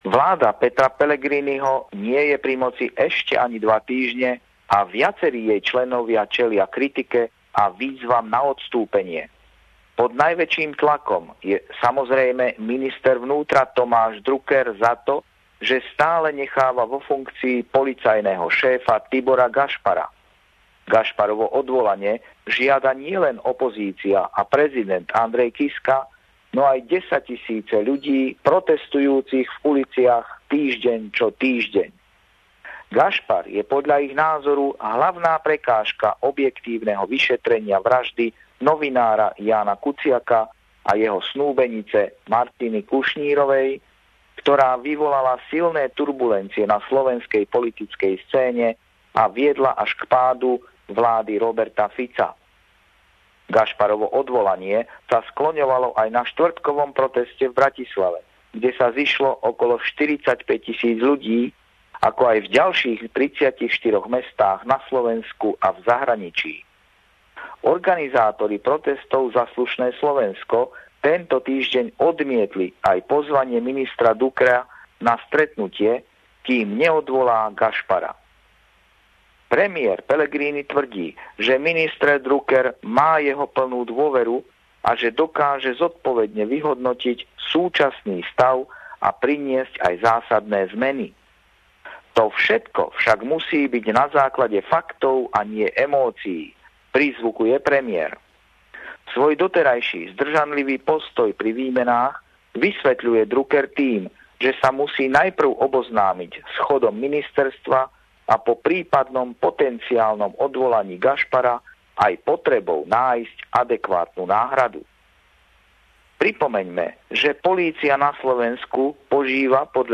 Pravideľný komentár